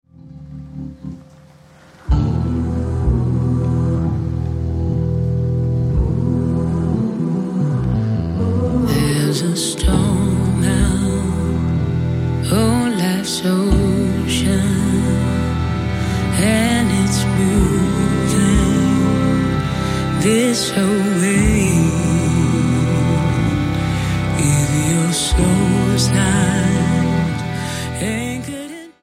STYLE: R&B
drone-based